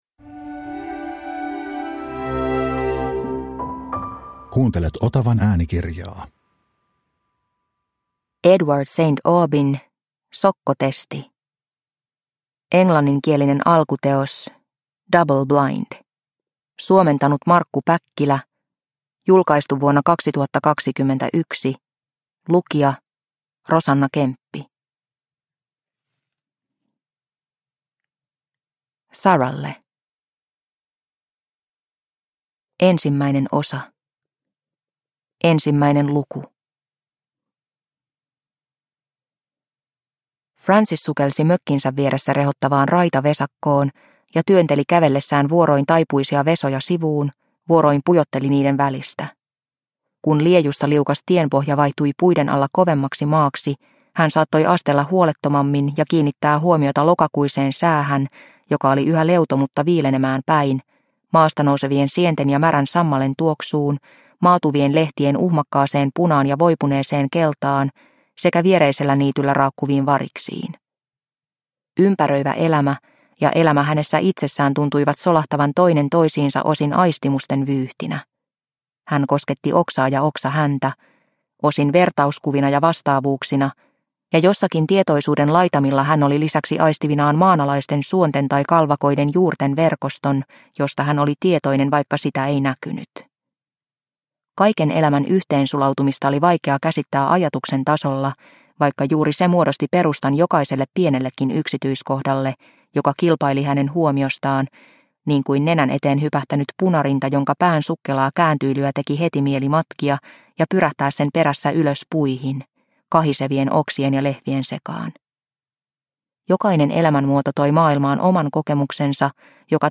Sokkotesti – Ljudbok – Laddas ner